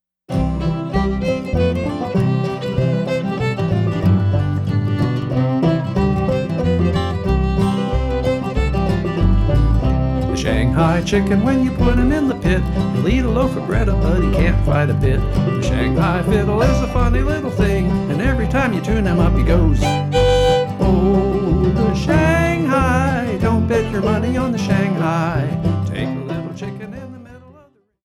Old Time Music of SW Pennsylvania
fiddle
banjo, fife, accordion
guitar
upright bass Between 1928 and 1963